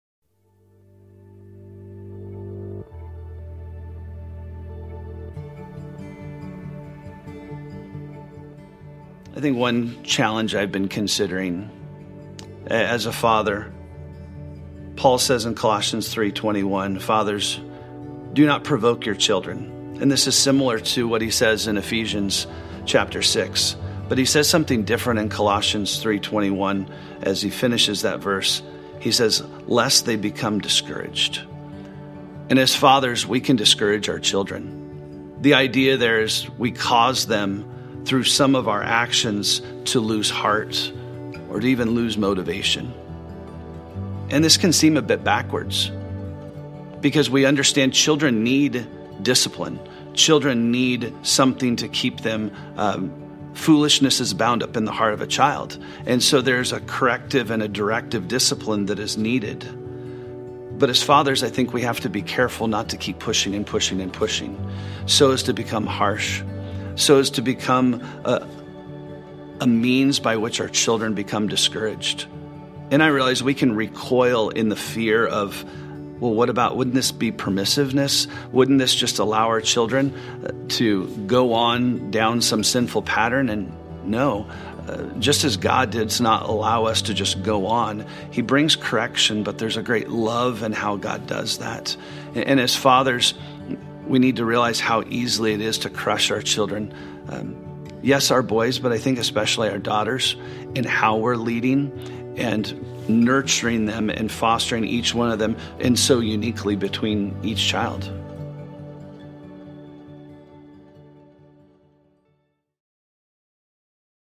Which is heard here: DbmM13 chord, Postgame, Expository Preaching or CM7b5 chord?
Expository Preaching